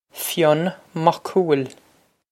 Fionn Mac Cumhaill Fyun Mok Koo-il
Pronunciation for how to say
This is an approximate phonetic pronunciation of the phrase.